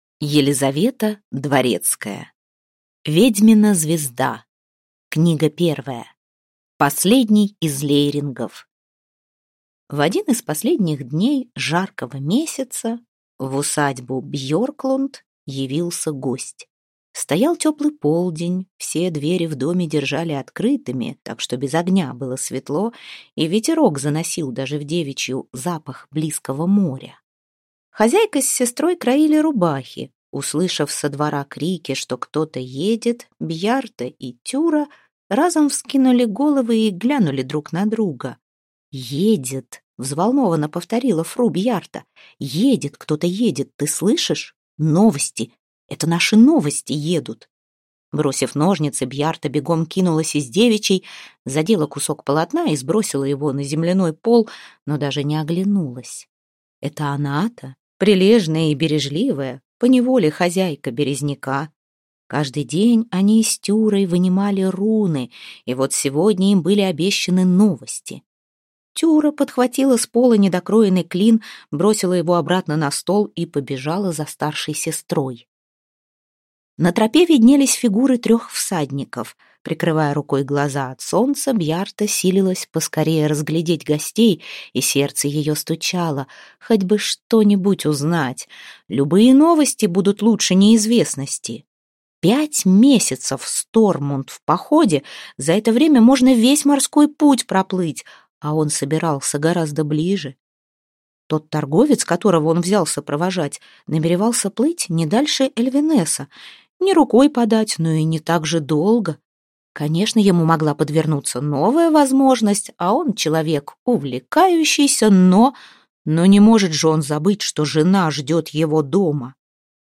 Аудиокнига Ведьмина звезда. Книга 1: Последний из Лейрингов | Библиотека аудиокниг